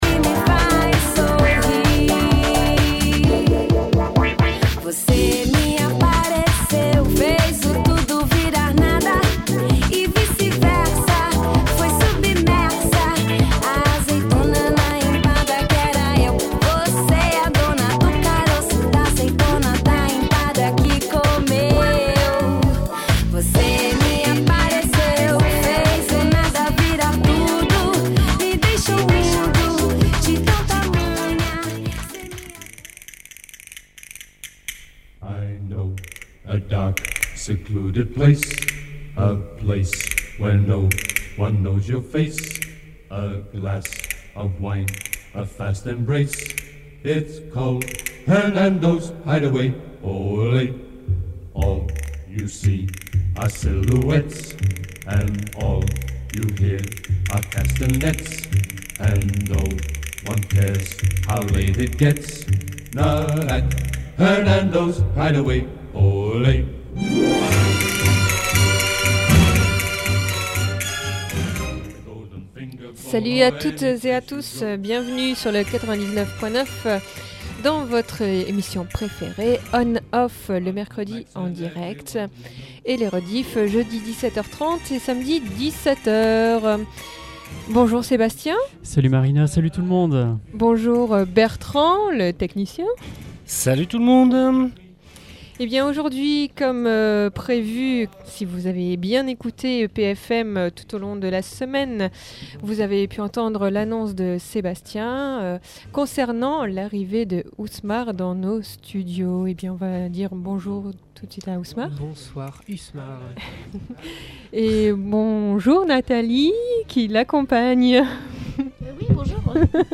Si vous n’avez pas pu en profiter : Une heure d’mission pour l’entendre s’exprimer, et jouer en direct !